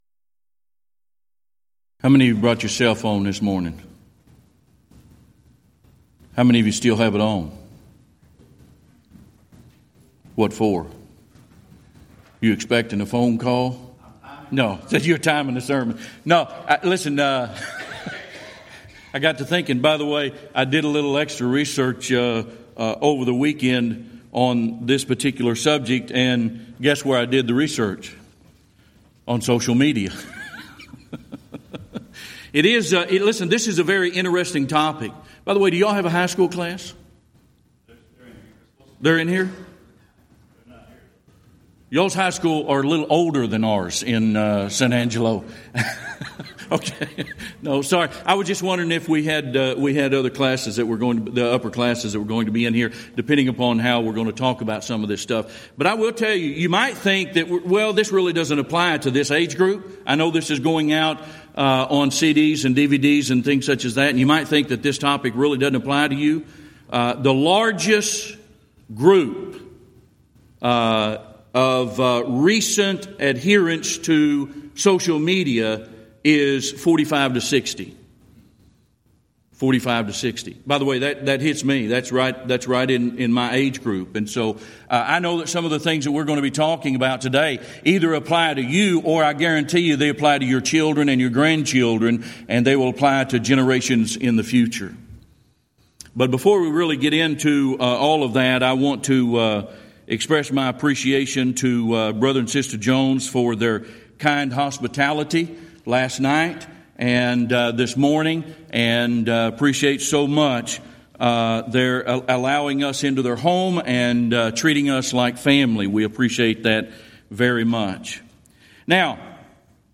Event: 5th Annual Back to the Bible Lectures
this lecture